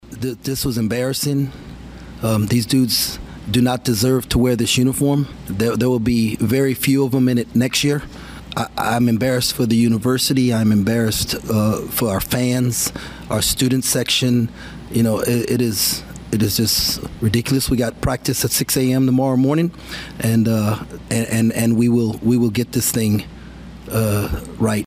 Tang had a brief postgame press conference, where he called out his players for not being deserving of wearing a K-State uniform.